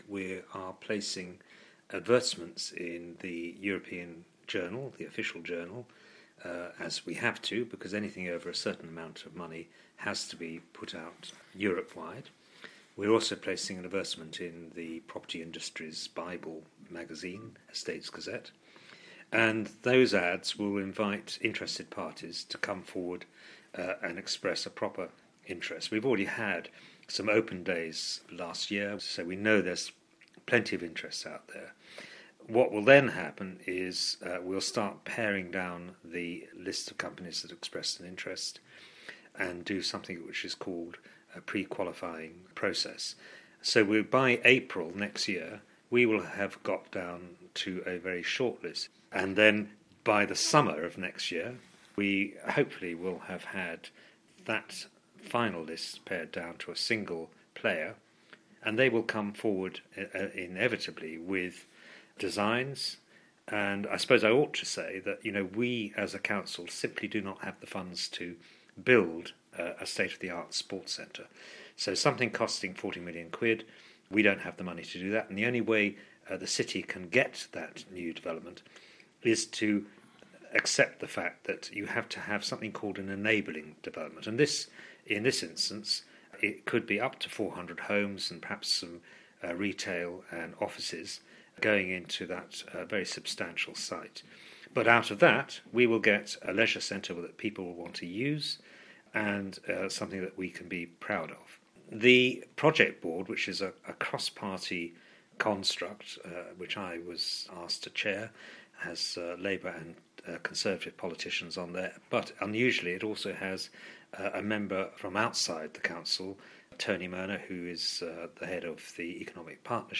Brighton & Hove City Council is advertising for developers to come forward with proposals to create a new leisure centre on Hove's King Alfred site. Hear the culture committee chair Councillor Geoffrey Bowden, pictured, outline the process for finding a development partner, the council's ambitions for the new facility and the need for an enabling development to fund the project.